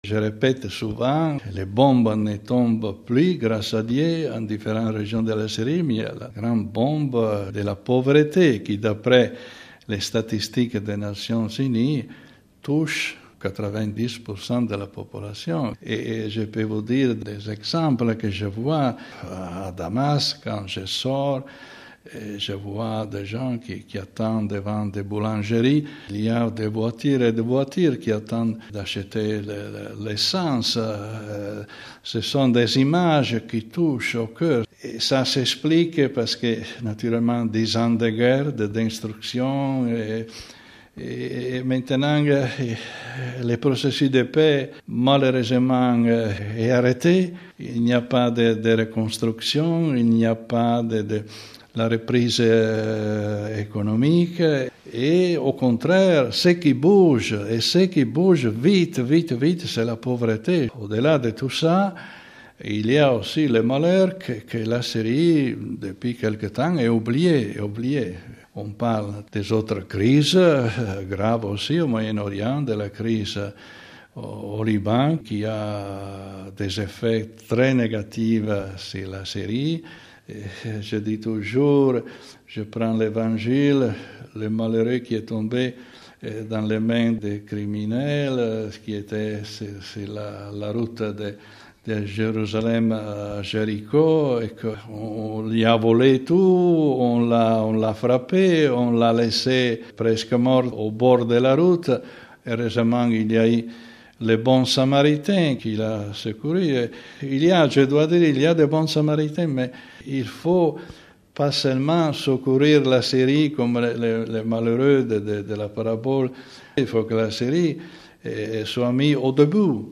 Entretien avec le cardinal Mario Zenari